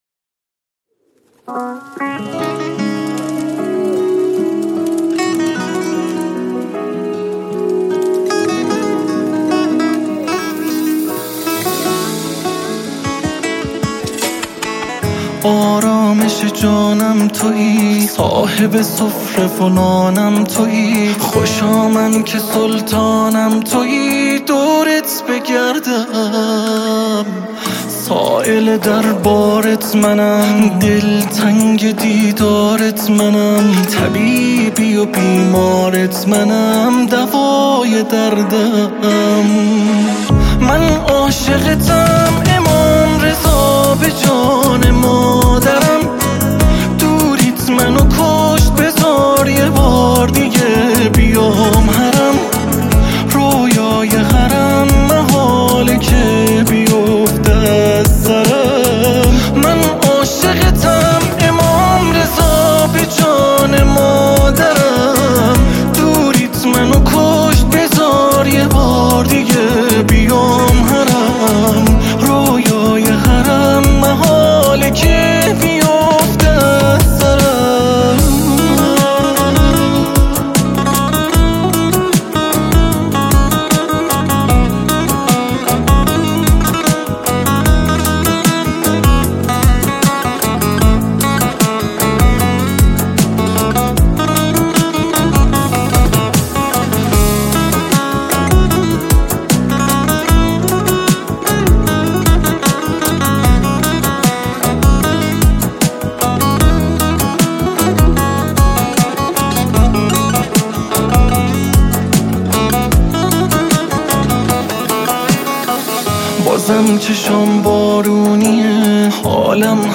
قطعه‌ی احساسی و دلنشین
با صدای گرم و جان‌بخش